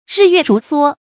日月如梭 注音： ㄖㄧˋ ㄩㄝˋ ㄖㄨˊ ㄙㄨㄛ 讀音讀法： 意思解釋： 日和月像穿梭似地運行。比喻光陰過得快。